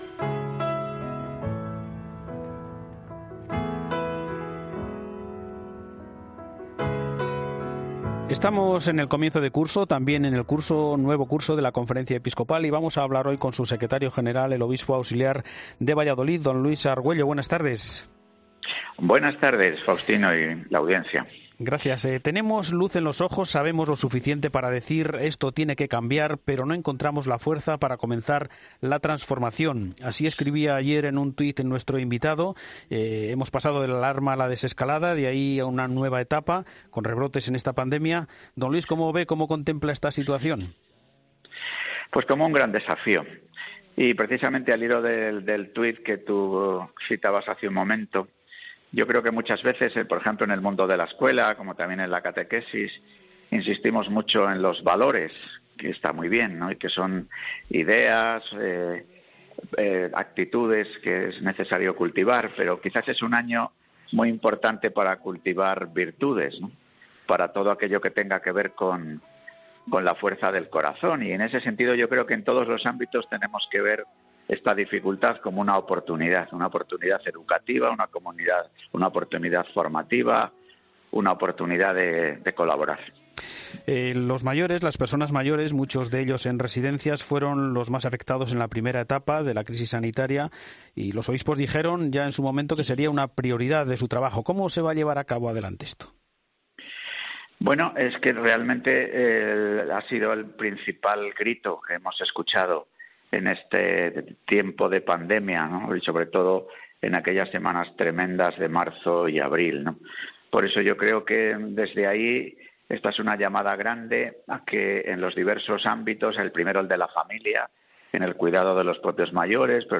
El Secretario General de la Conferencia Episcopal Española, Don Luis Argüello, ha pasado por los micrófonos de 'El Espejo' en este inicio de temporada radiofónica para el Grupo COPE.